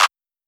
TM88 - CLAP (3).wav